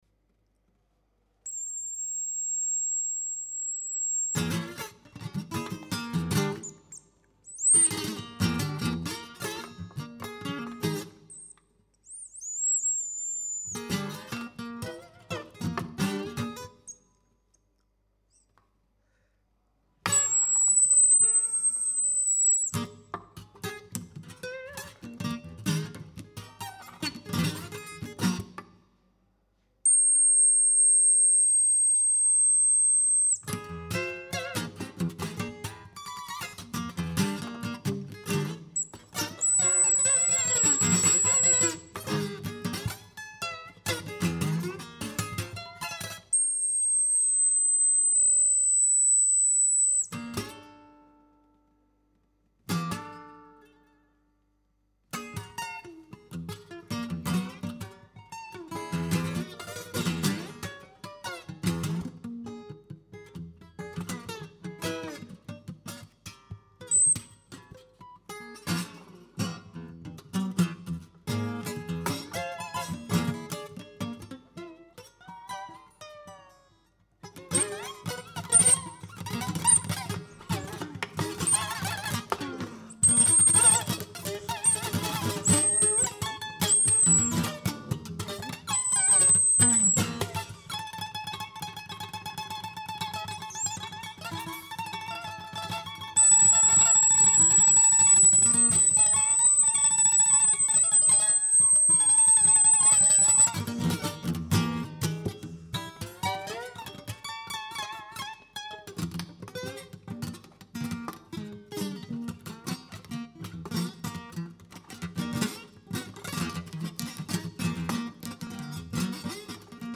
egitasmo improbisatzaile eta esperimentala da
paisai goxo eta atseginak